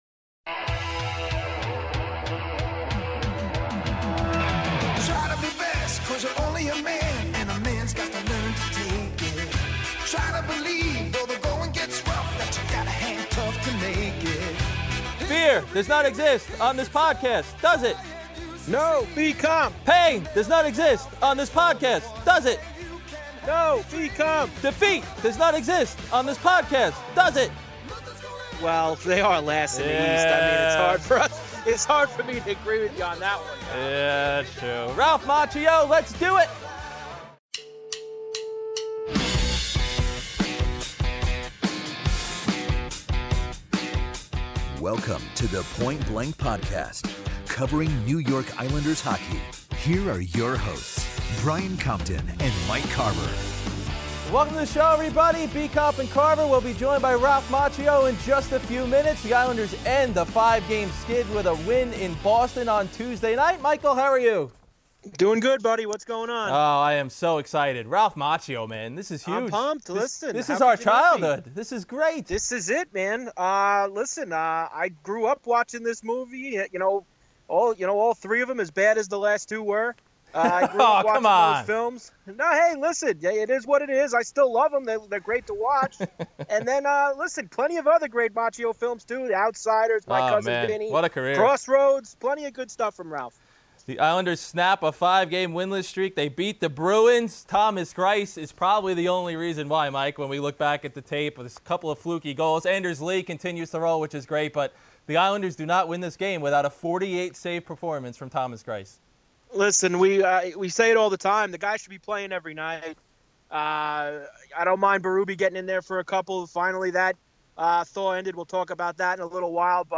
Later, star of The Karate Kid, Ralph Macchio, calls in to discuss his bobblehead giveaway on Friday night and growing up as an Islanders fan.